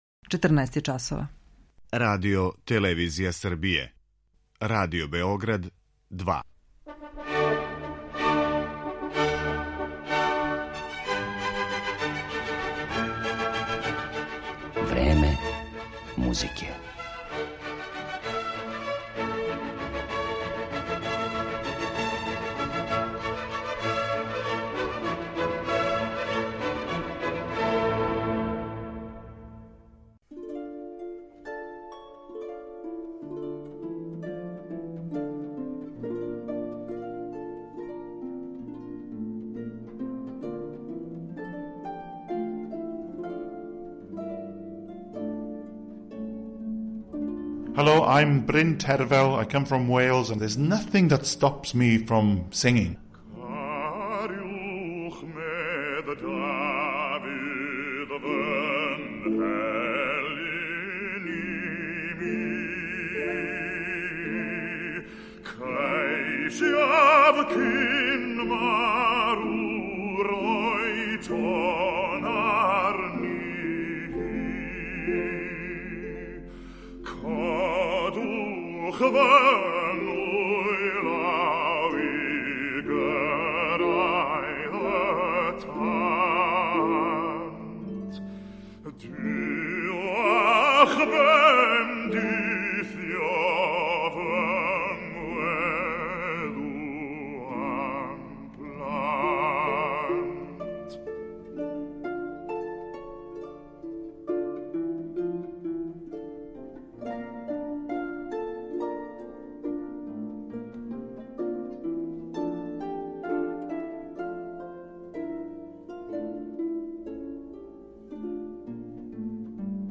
Ипак, Тервел је свестрани уметник па ћемо га представити и као оперског и као концертног певача, као и кроз ексклузивни интервју.